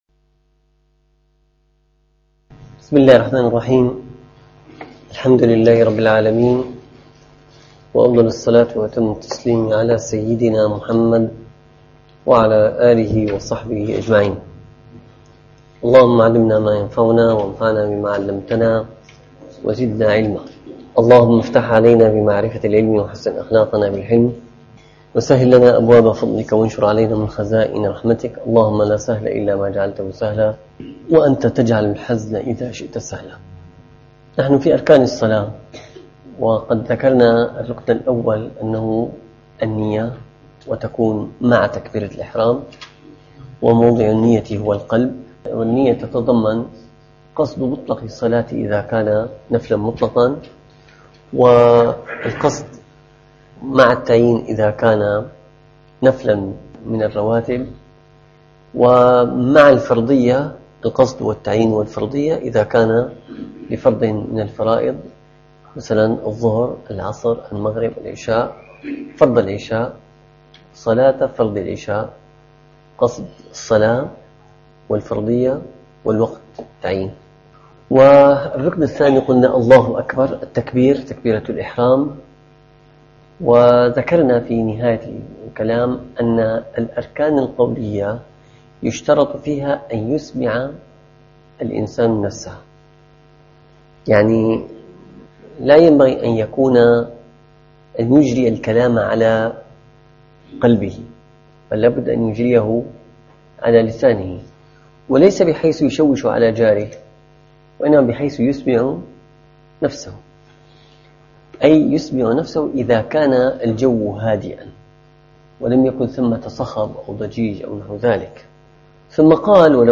- الدروس العلمية - الفقه الشافعي - المنهاج القويم شرح المقدمة الحضرمية - تكملة لصفة الصلاة.